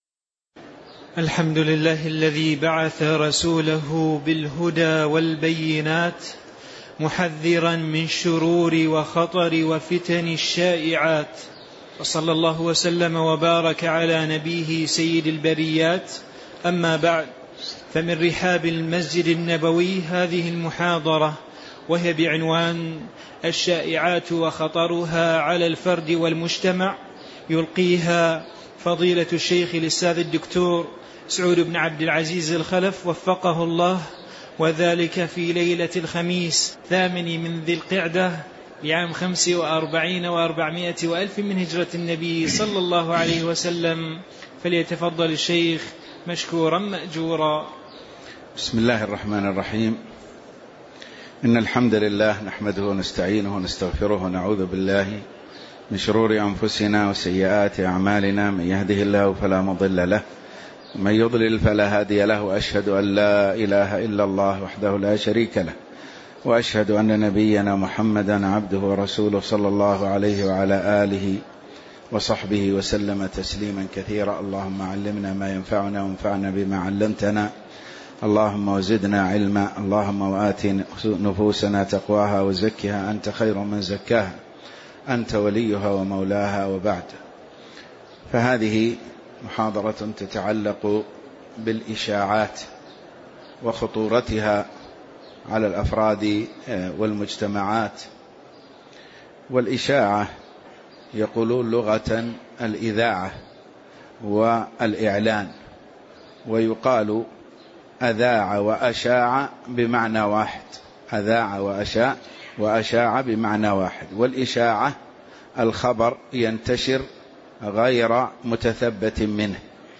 محاضرة الشائعات وخطرها على الفرد والمجتمع وفيها: تعريف الشائعات، وأنواعها وتعرض الأنبياء للشائعات، وقصة حادثة الإفك، وما تؤول إليه
تاريخ النشر ٧ ذو القعدة ١٤٤٥ المكان: المسجد النبوي الشيخ